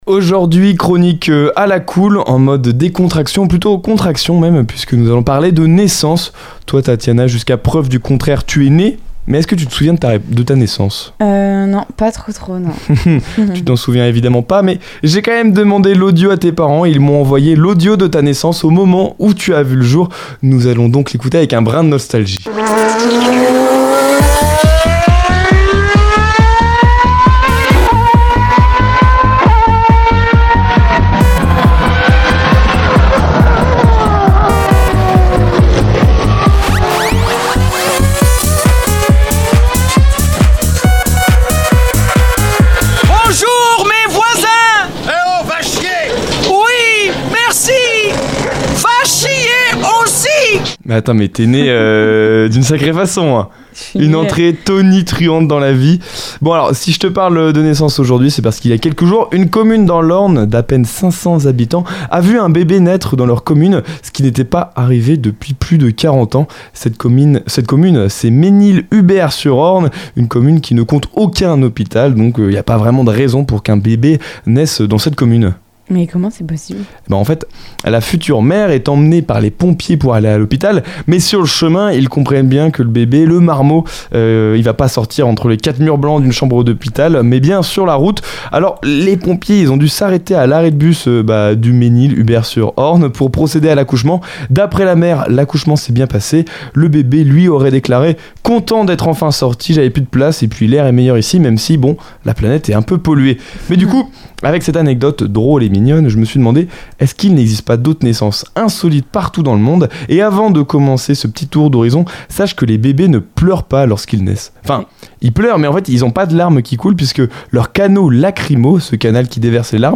Réponse dans cette chronique...